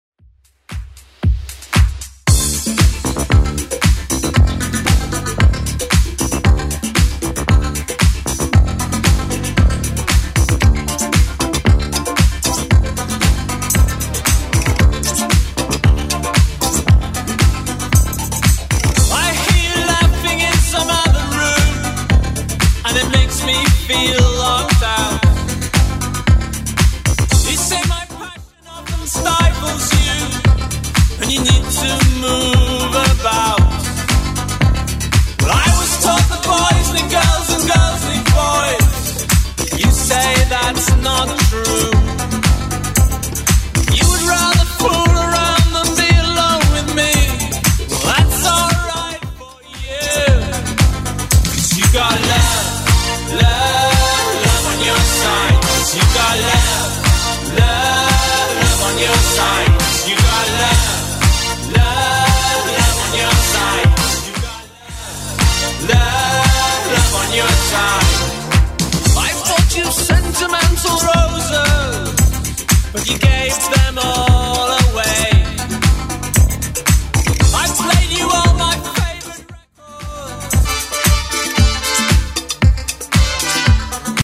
BPM: 115 Time